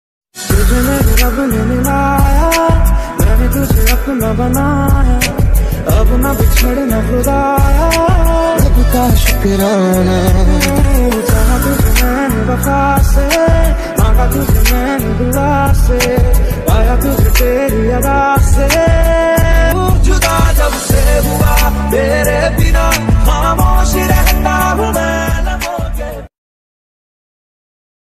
Música Clasica